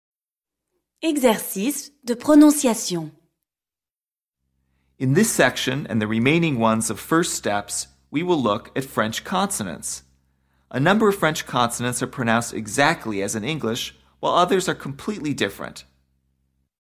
PRONONCIATION
ch – This combination is pronounced like “sh” as in the English word “shirt.”
2. When it is followed by the vowels e or i, it is pronounced like the “z” in “azure.”